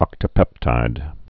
(ŏktə-pĕptīd)